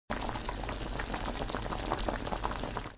煮东西.mp3